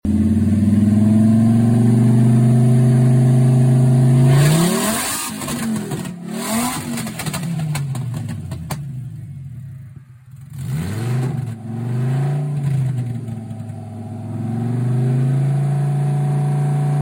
Vw Golf 2.3 VR5 sound sound effects free download
Vw Golf 2.3 VR5 sound without exhaust